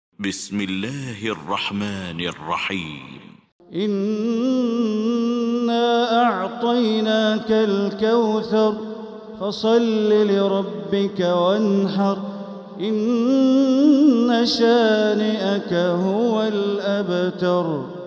المكان: المسجد الحرام الشيخ: معالي الشيخ أ.د. بندر بليلة معالي الشيخ أ.د. بندر بليلة الكوثر